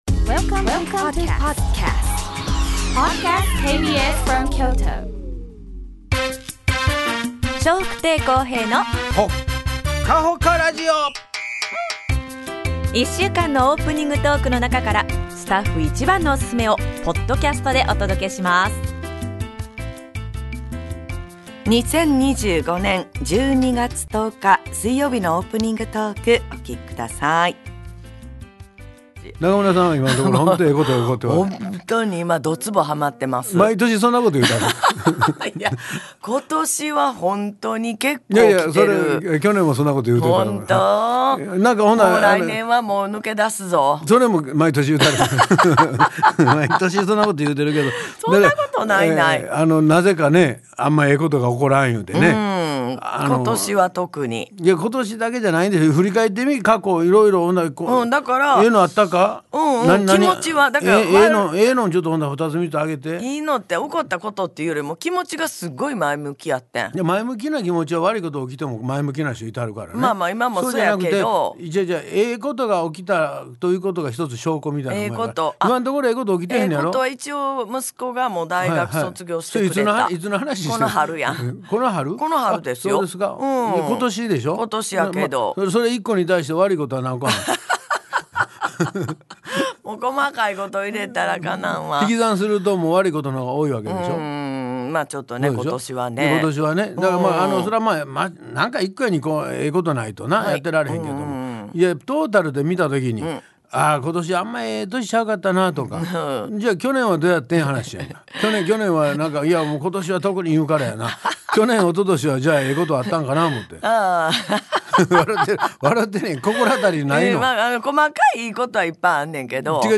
2025年12月10日のオープニングトーク